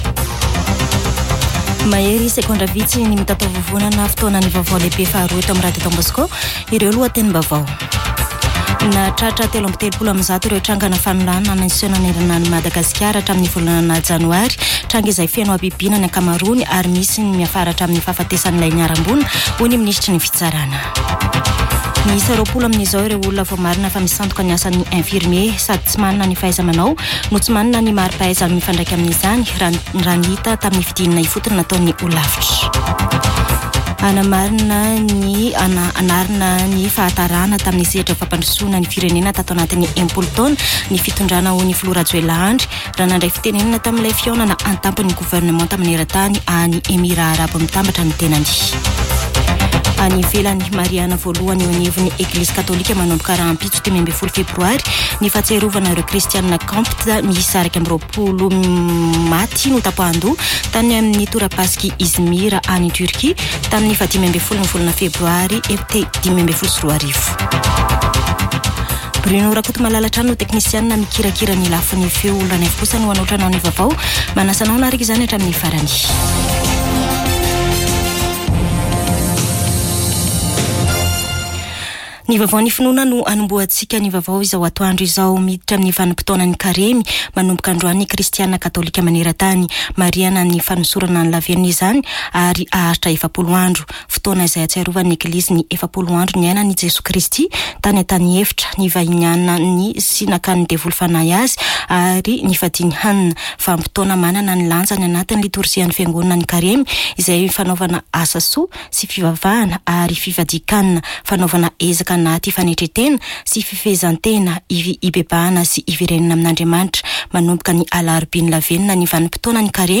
[Vaovao antoandro] Alarobia 14 febroary 2024